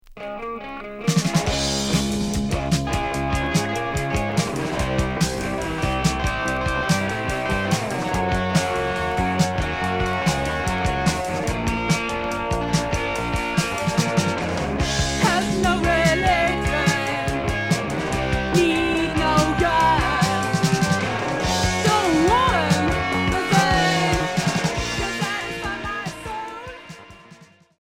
Punk rock Unique 45t